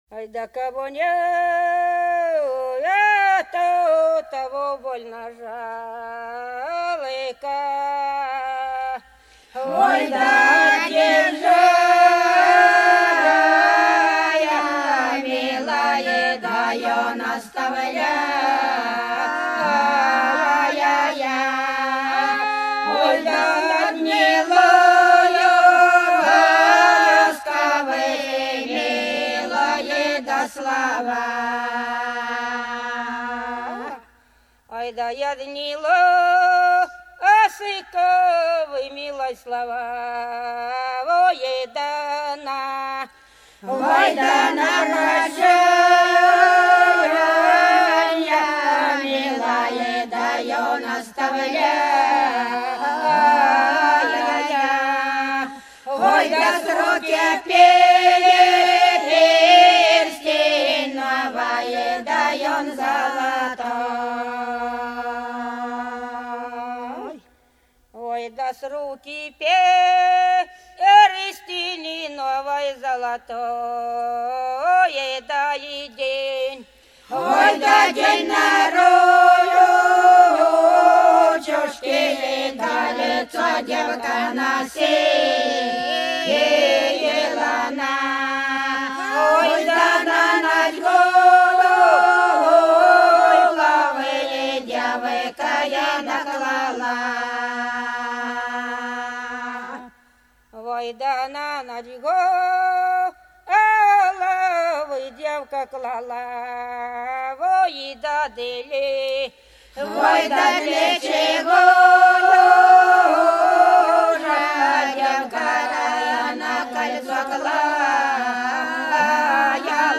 Вдоль по улице пройду (Бутырки Репьёвка) 019. Кого нету, того больно жалко — протяжная песня.